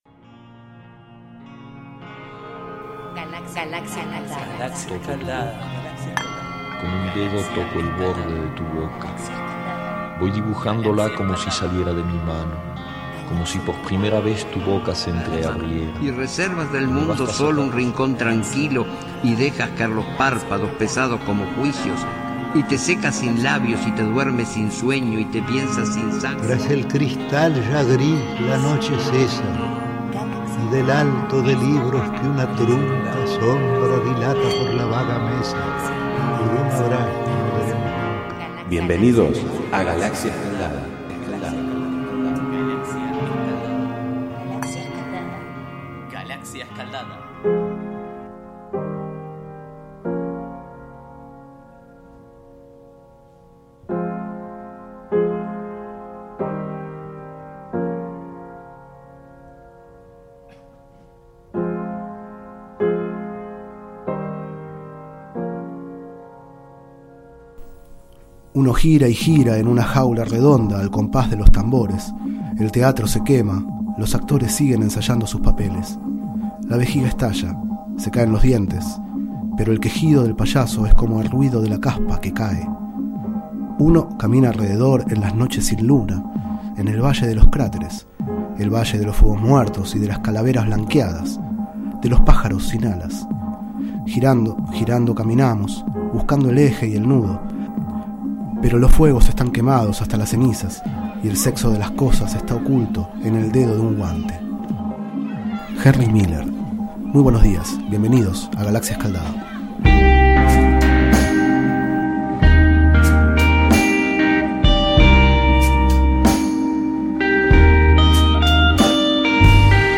Este es el 02º micro radial, emitido en los programas Enredados, de la Red de Cultura de Boedo, y En Ayunas, el mañanero de Boedo, por FMBoedo, realizado el 30 de marzo de 2013, sobre el libro La ciudad de los puentes obsoletos, de Federico Pazos.